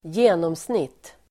Uttal: [²j'e:nåmsnit:]